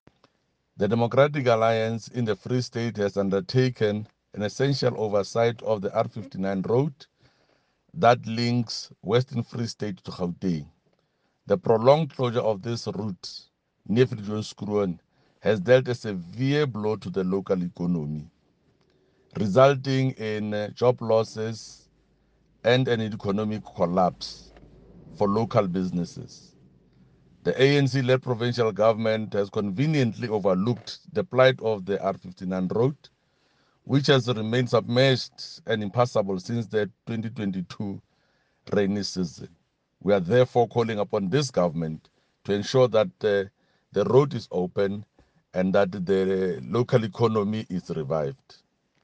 Eng-voice-Jafta.mp3